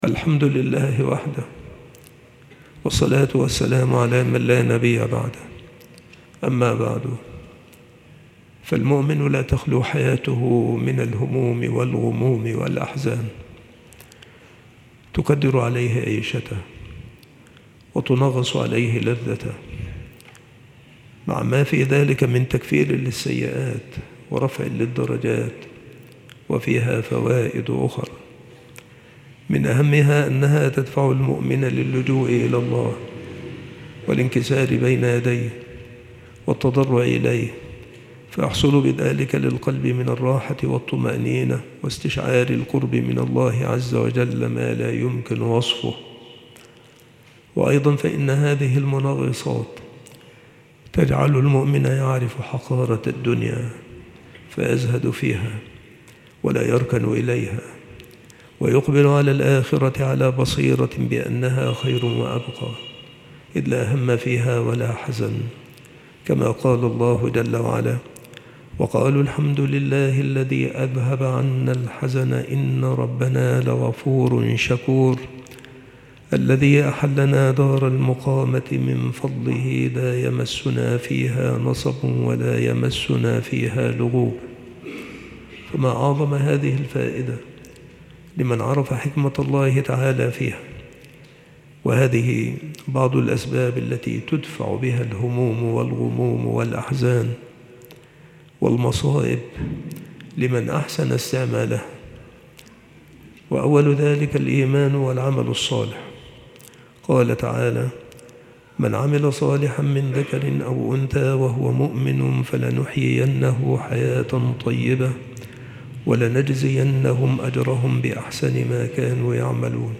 مكان إلقاء هذه المحاضرة بالمسجد الشرقي - سبك الأحد - أشمون - محافظة المنوفية - مصر